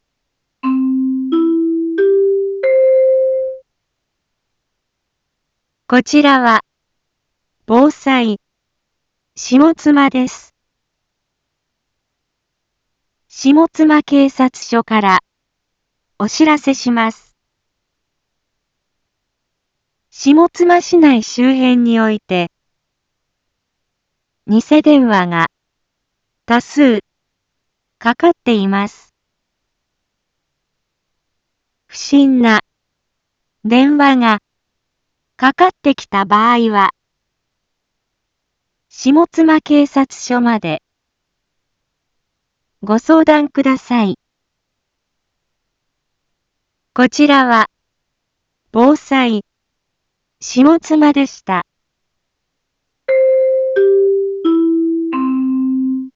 一般放送情報
BO-SAI navi Back Home 一般放送情報 音声放送 再生 一般放送情報 登録日時：2022-09-21 12:30:59 タイトル：ニセ電話詐欺にご注意を インフォメーション：こちらは、防災、下妻です。